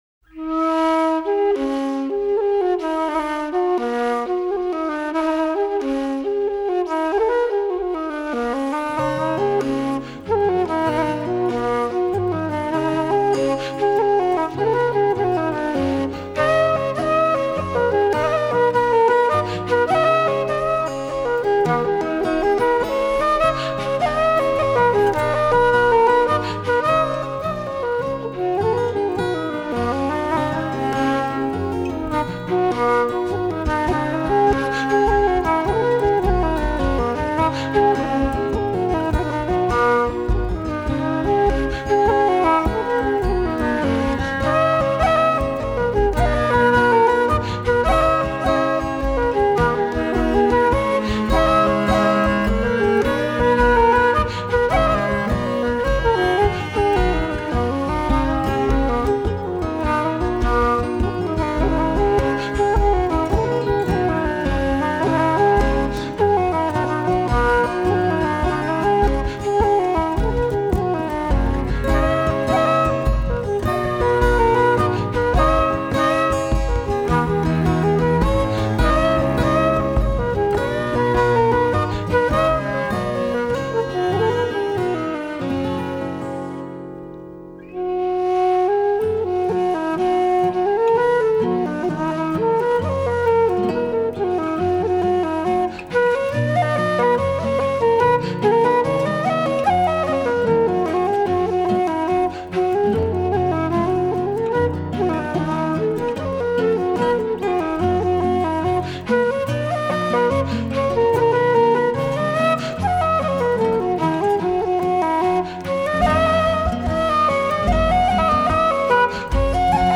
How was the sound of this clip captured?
I spend a wonderful day at home as the storm whirls around me. I learn flute tunes.